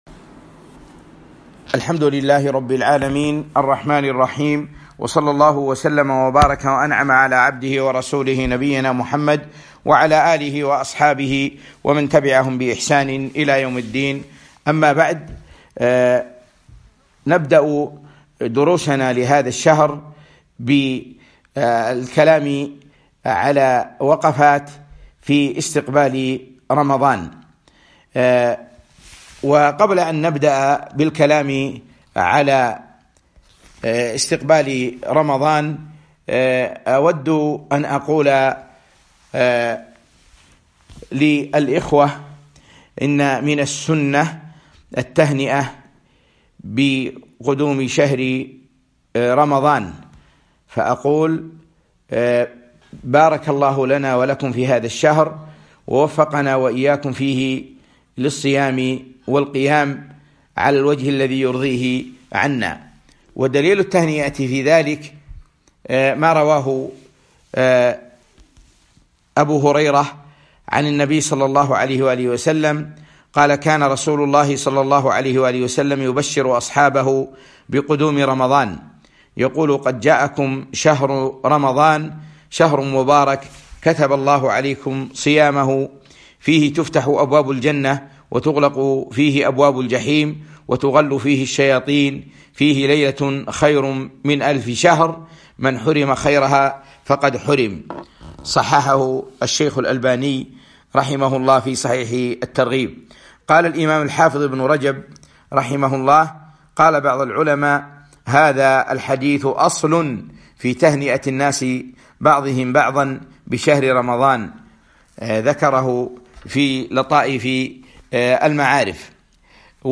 وقفات مع الصيام - محاضرة عبر البث رمضان 1441